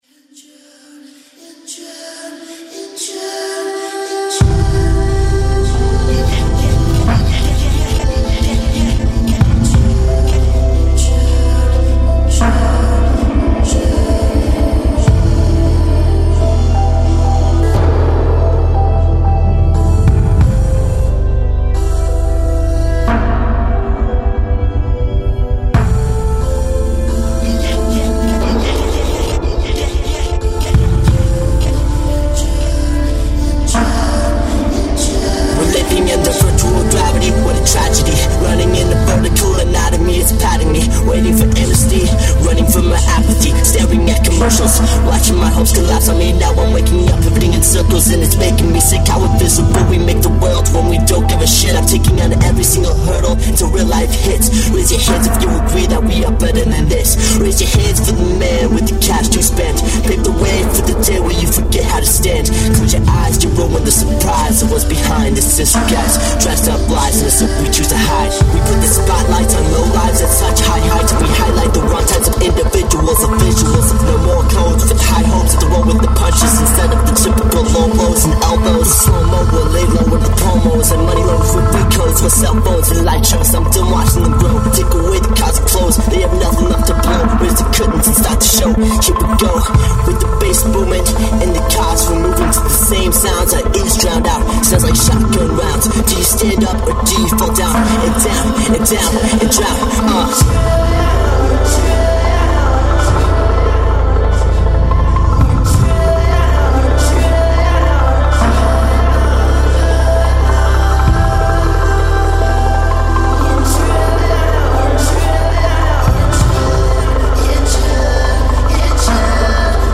alternative hip hop